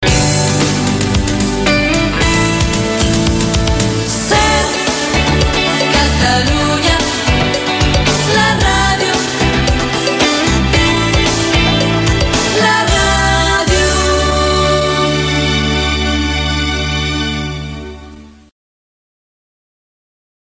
Indicatiu de la cadena